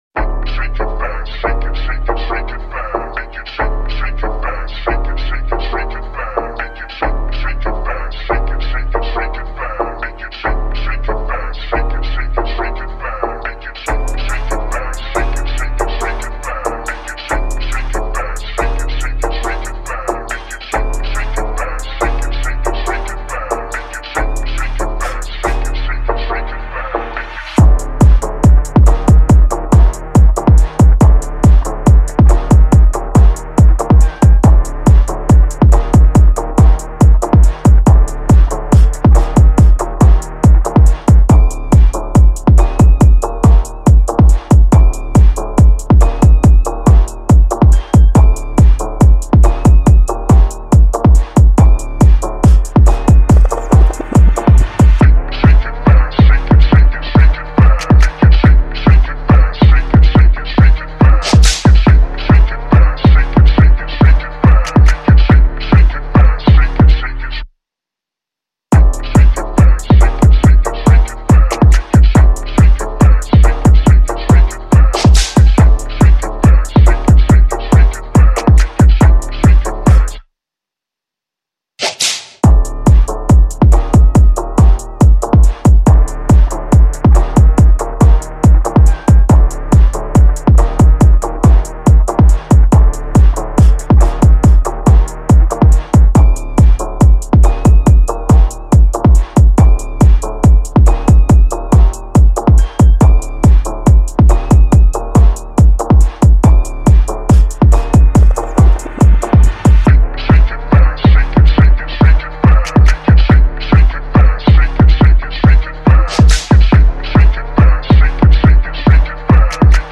This is the official instrumental
2024 in New Jersey Club Instrumentals